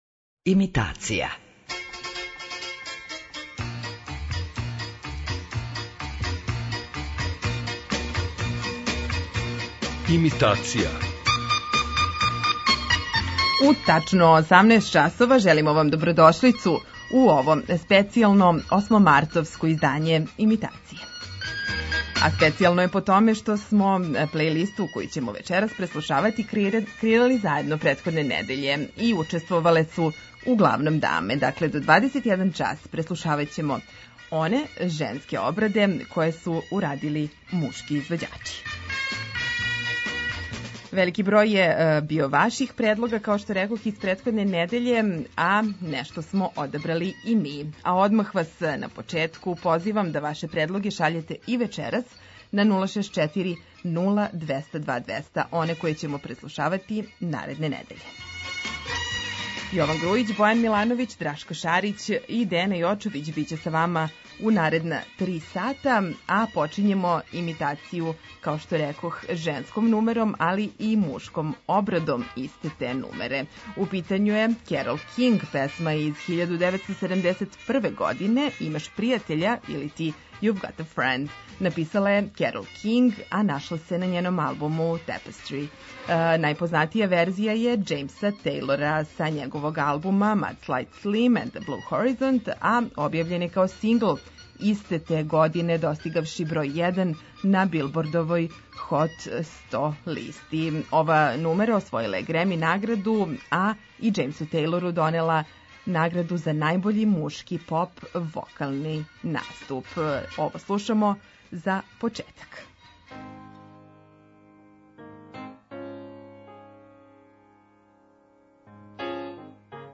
У данашњој емисији упоређиваћемо оригиналне верзије песама које су прве снимиле жене и њихове обраде које су урадили мушки вокали.